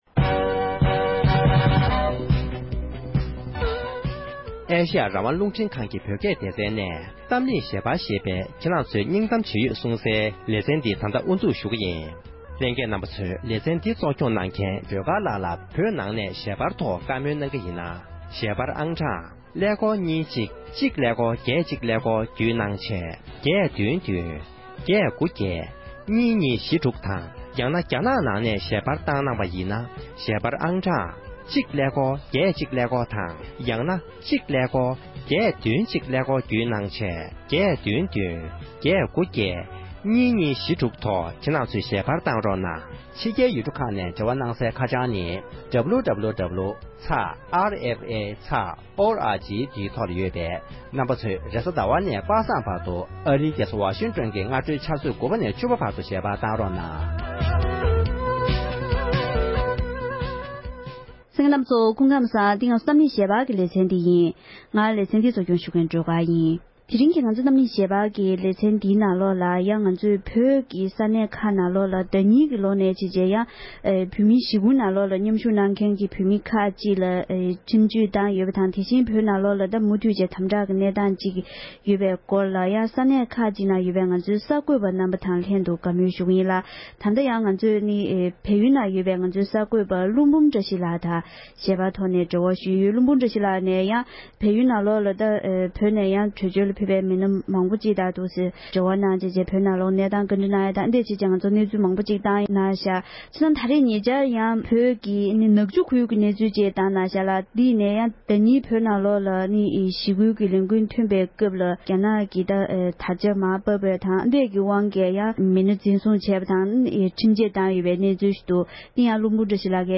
གཏམ་གླེང་ཞལ་པར
འབྲེལ་ཡོད་མི་སྣའི་ལྷན་གླེང་མོལ་གནང་བར་གསན་རོགས༎